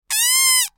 دانلود آهنگ باد 82 از افکت صوتی طبیعت و محیط
دانلود صدای باد 82 از ساعد نیوز با لینک مستقیم و کیفیت بالا
جلوه های صوتی